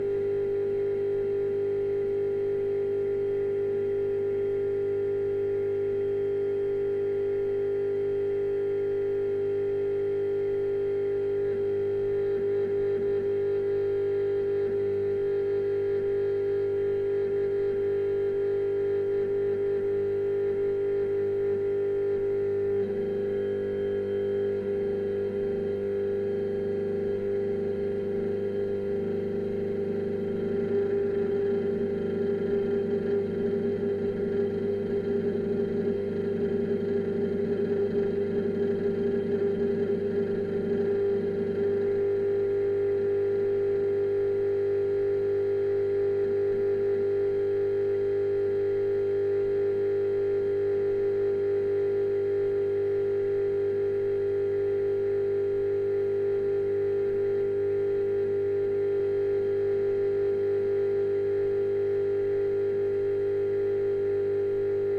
Transformer sound
• Category: Transformer sounds